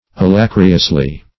alacriously - definition of alacriously - synonyms, pronunciation, spelling from Free Dictionary Search Result for " alacriously" : The Collaborative International Dictionary of English v.0.48: Alacriously \A*lac"ri*ous*ly\, adv.